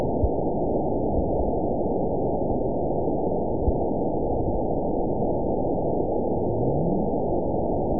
event 920414 date 03/23/24 time 22:12:08 GMT (1 year, 1 month ago) score 9.49 location TSS-AB04 detected by nrw target species NRW annotations +NRW Spectrogram: Frequency (kHz) vs. Time (s) audio not available .wav